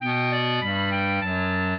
minuet1-3.wav